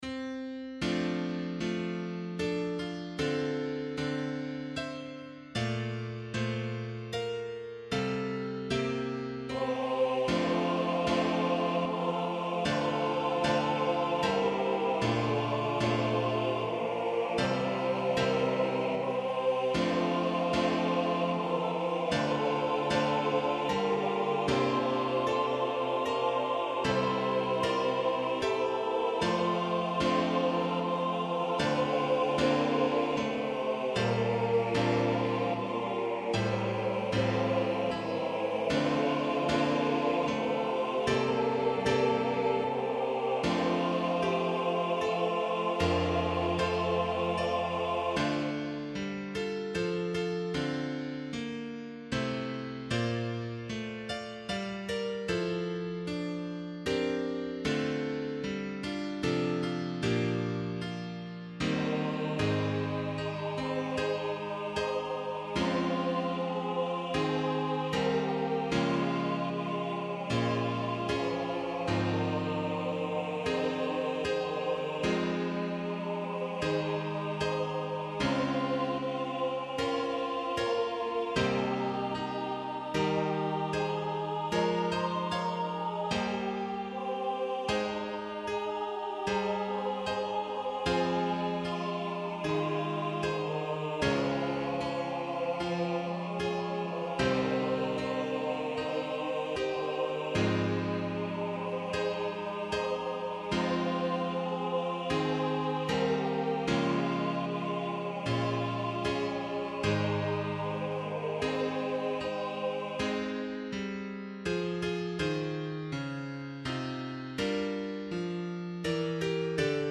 I was asked to write a fairly simple SATB choir arrangement of Amazing Grace.
The second verse is a polyphonic duet with tenors singing the melody while all the women sing the counter melody.
All voices sing in unison the third verse.
Voicing/Instrumentation: SATB , Duet We also have other 59 arrangements of " Amazing Grace ".